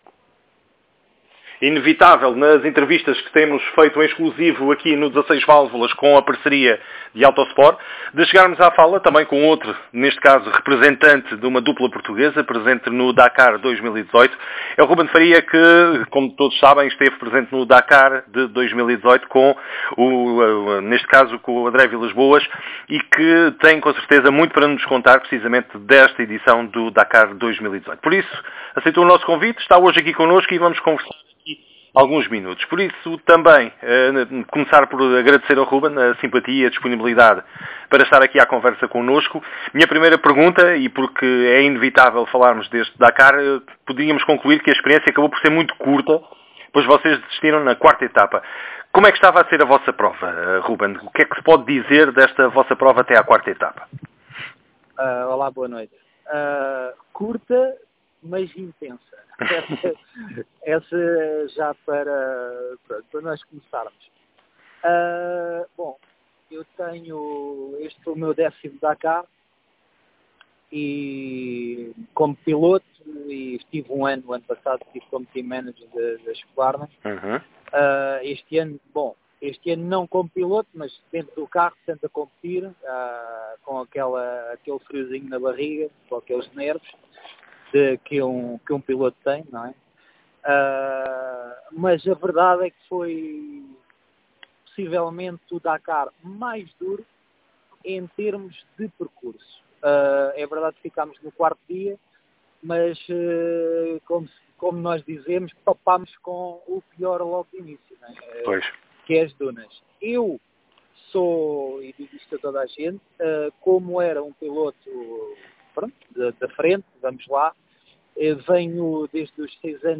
Ruben Faria fez com o AutoSport/16 Válvulas o ‘debriefing’ do Dakar, uma prova em que se estreou no banco do lado direito de um carro, ao lado de André Villas Boas. A experiência foi curta, desistiram na 4ª etapa, mas há muito para contar: